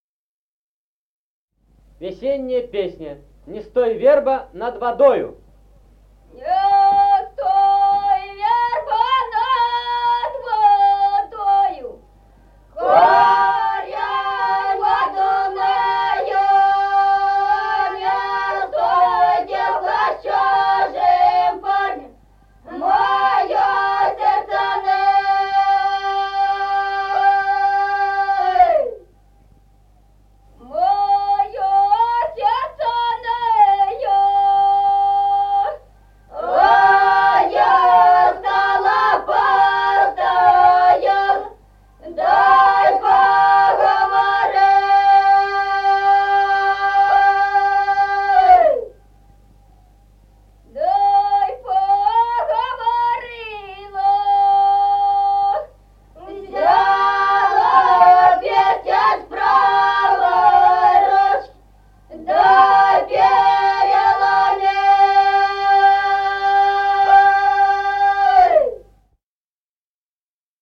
Народные песни Стародубского района «Не стой, верба», весняная девичья.
с. Мишковка.